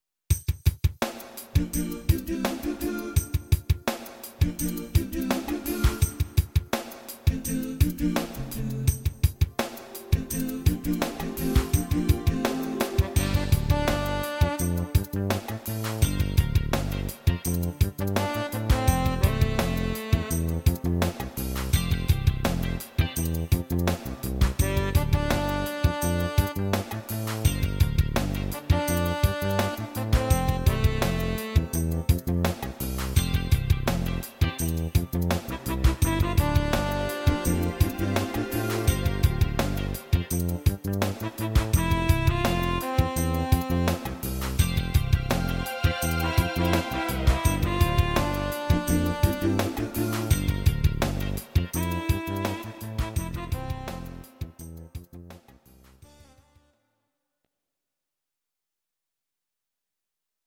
Audio Recordings based on Midi-files
cover